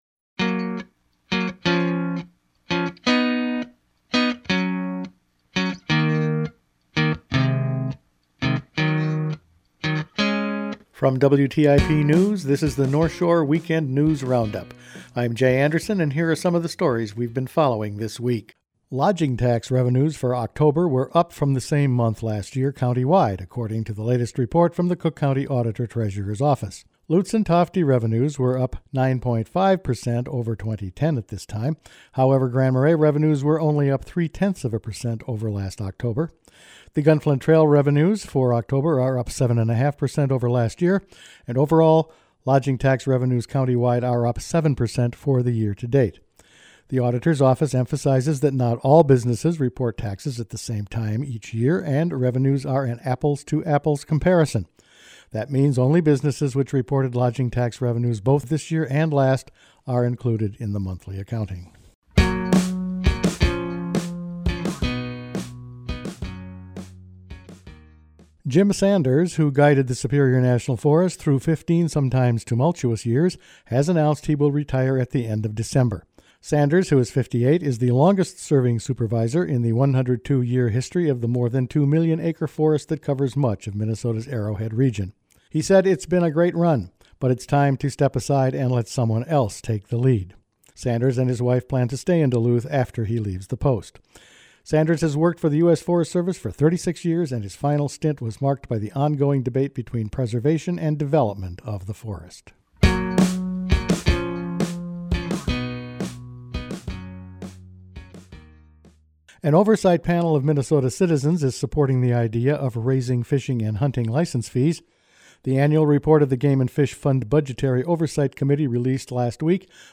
Weekend News Round-up for Dec. 3